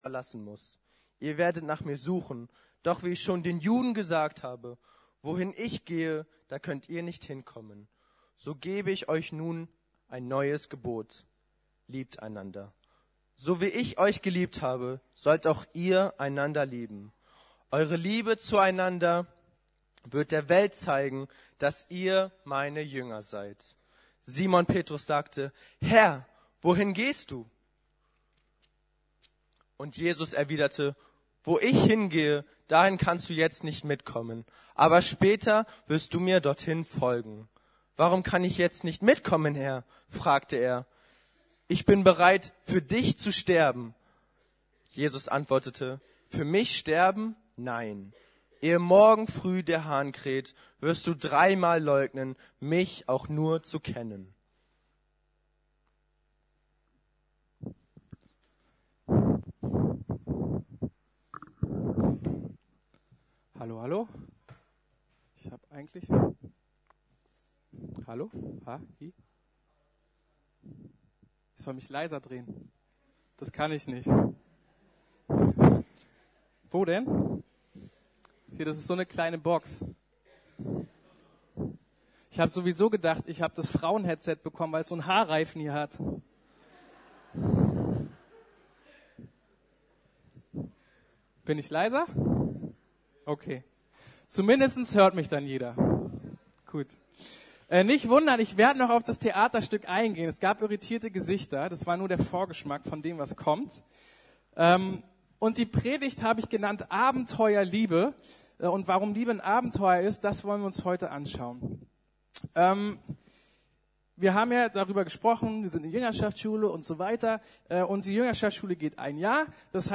Abenteuer Liebe ~ Predigten der LUKAS GEMEINDE Podcast